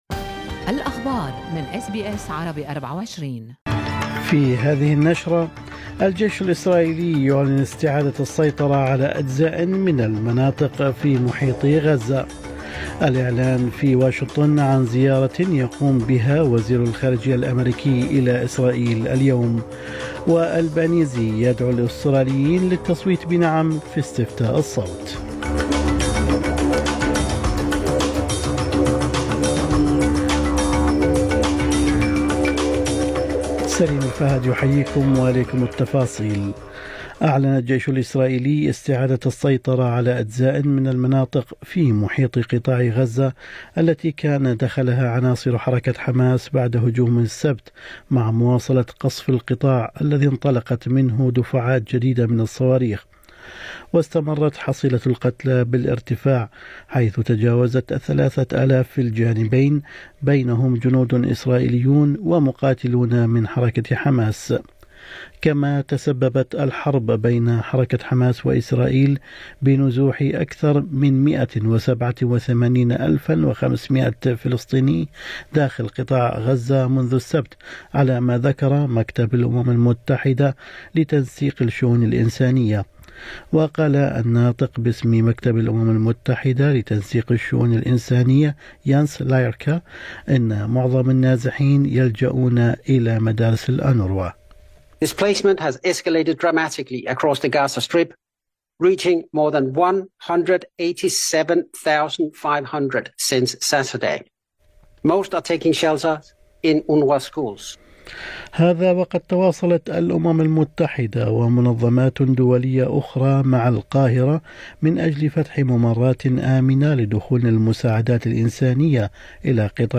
نشرة أخبار الصباح 11/10/2023